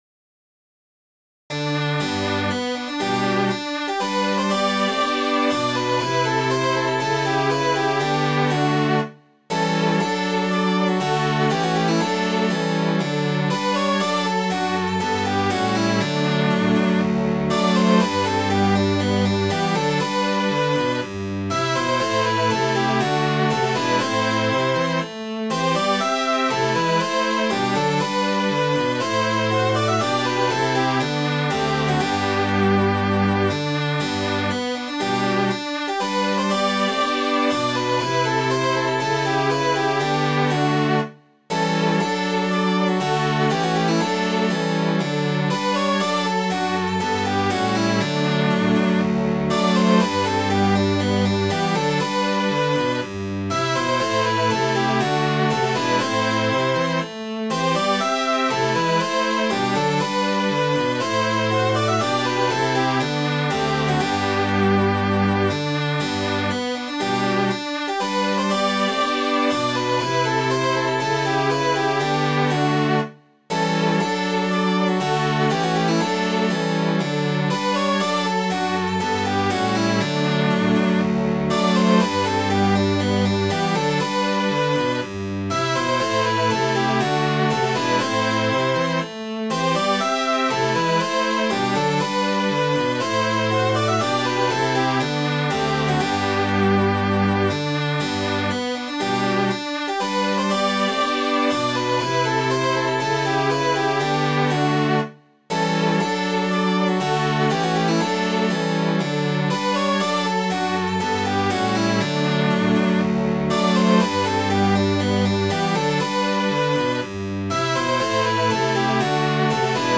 Midi File, Lyrics and Information to Come, Loose Every Sail to the Breeze